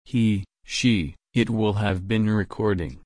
Partizip Perfekt
/ɹɪˈkɔːdɪd/